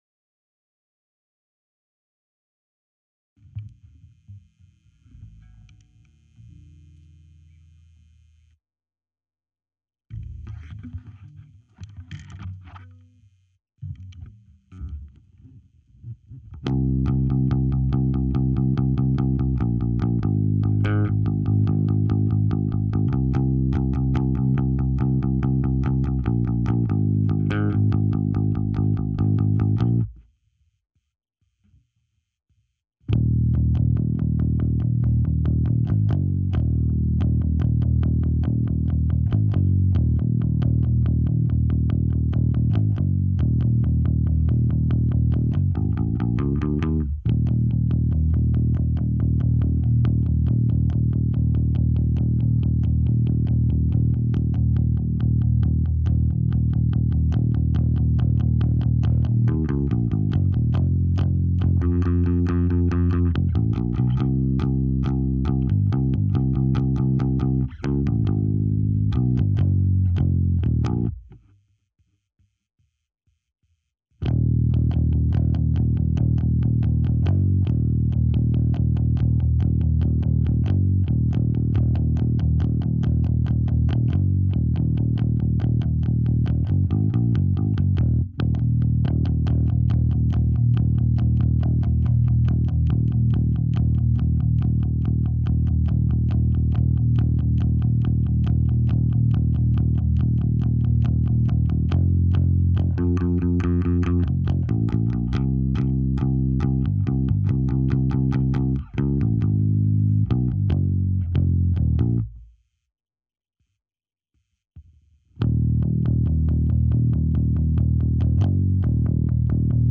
with the lead guitars removed